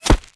bullet_impact_dirt_05.wav